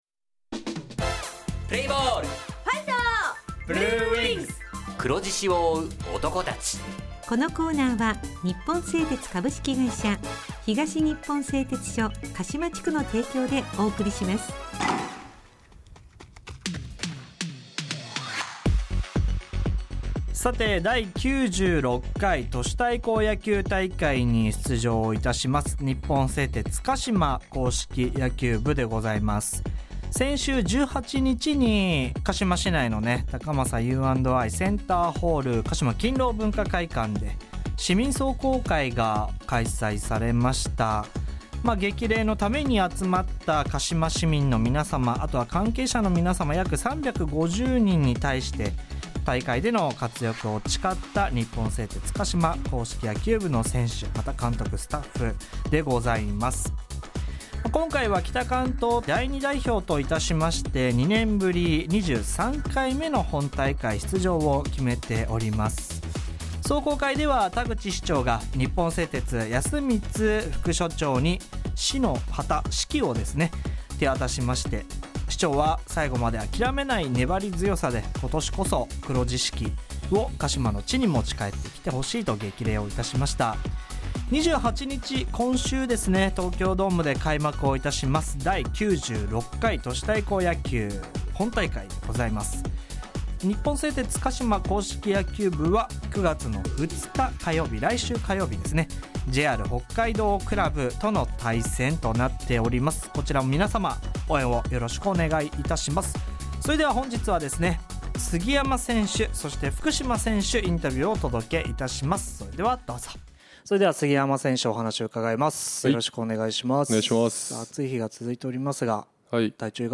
インタビュー
地元ＦＭ放送局「エフエムかしま」にて鹿島硬式野球部の番組放送しています。